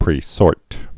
(prē-sôrt)